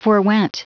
Prononciation du mot forwent en anglais (fichier audio)
Prononciation du mot : forwent